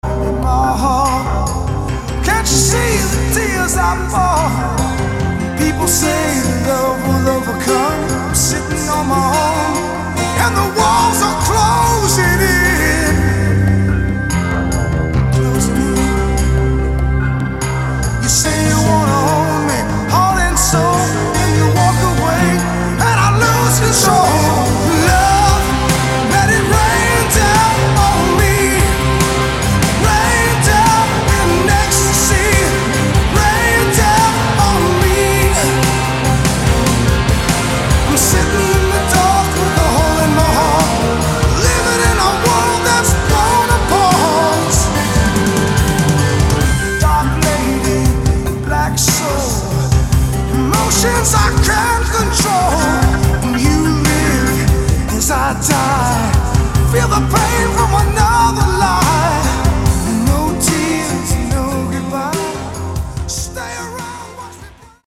Keyboards and Guitars
Drums
Bass
classic AOR act
24 track analogue recordings